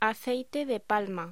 Locución: Aceite de palma